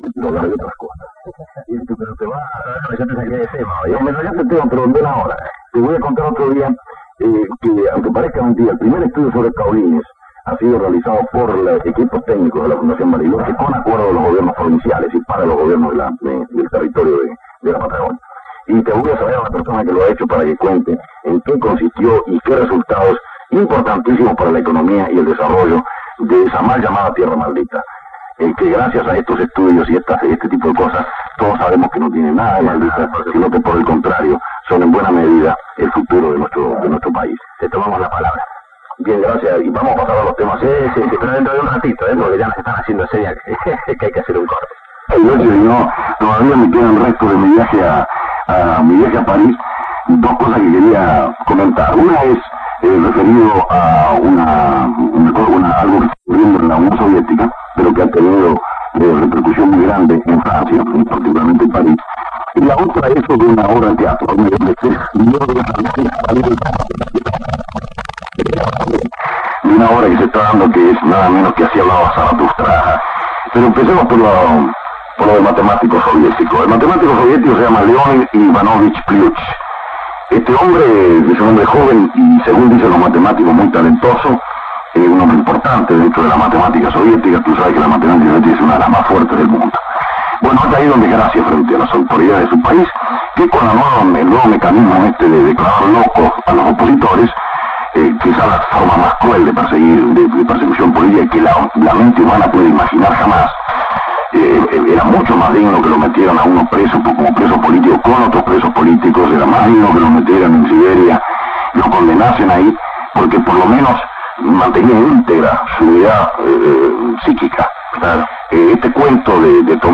En su programa 'Las 12 horas de la radio', Miguel Brascó entrevista a Jorge A. Sabato, transmitido por Radio Continental.